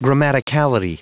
Prononciation du mot grammaticality en anglais (fichier audio)
Prononciation du mot : grammaticality